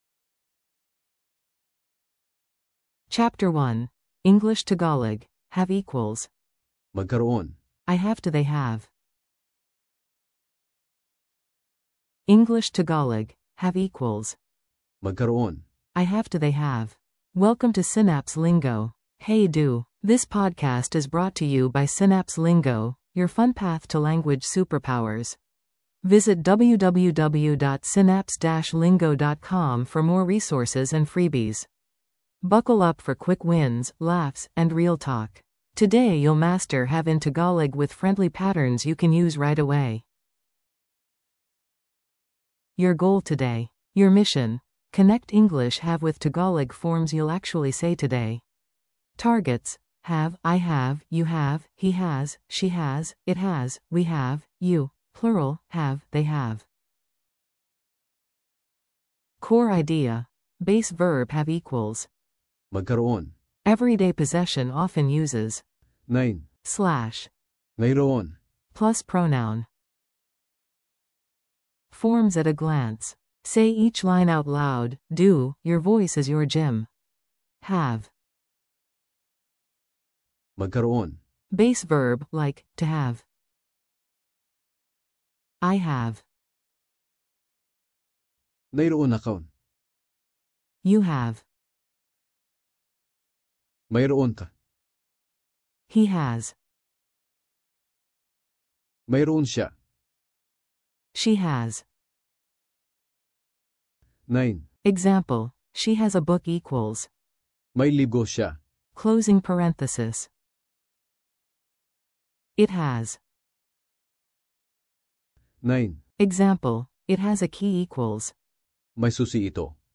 Audio for repeating & practicing
• ✔ eBooks + Audiobooks complete